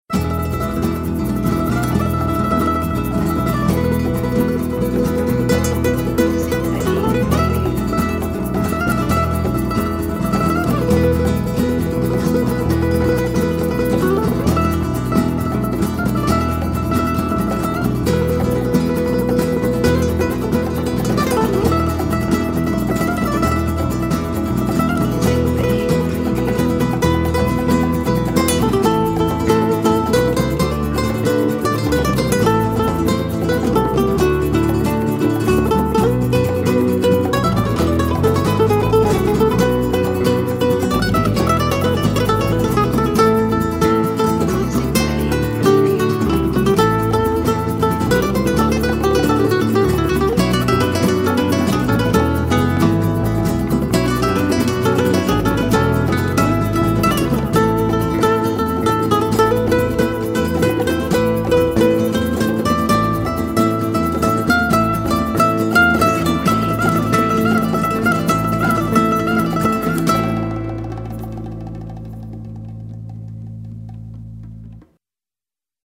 Acoustic royalty free music
Acoustic folk music for funny videos.